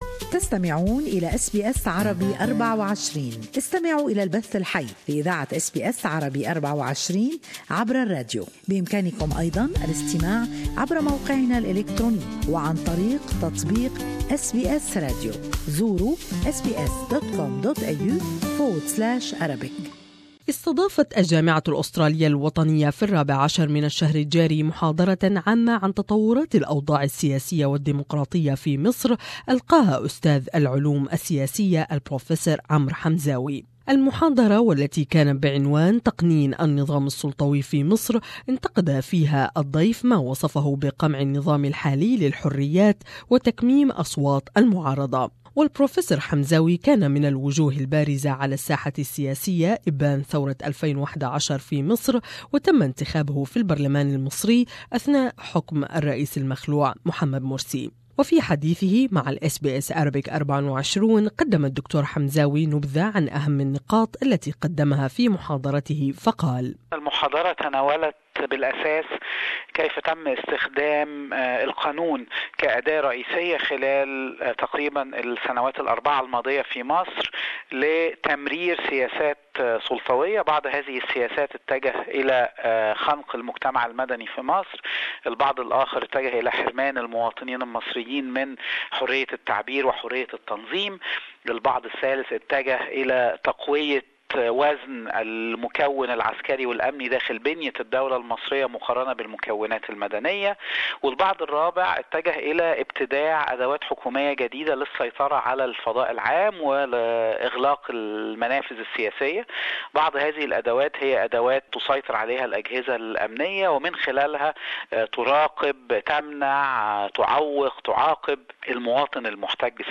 He opposed the then Islamist elected president Mohamed Morsi and went on to oppose the military coup of 2013 which ended his political career.More in this interview